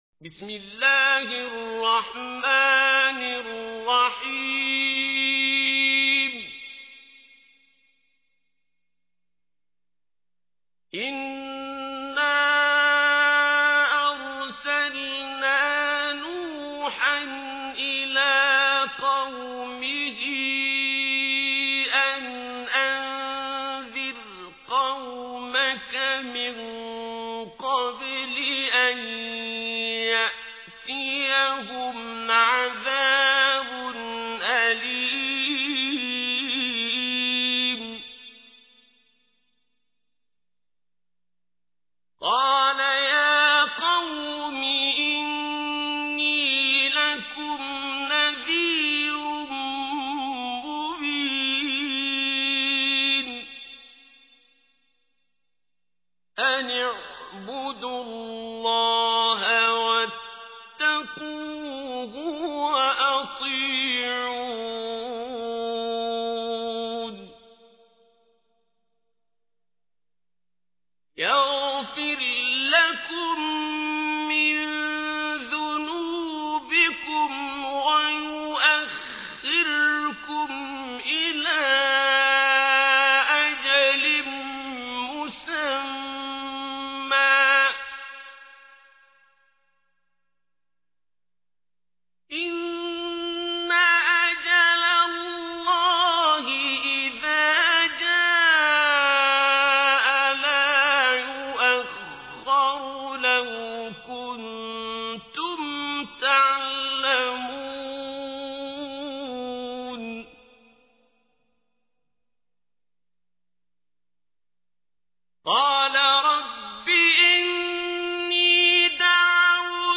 تحميل : 71. سورة نوح / القارئ عبد الباسط عبد الصمد / القرآن الكريم / موقع يا حسين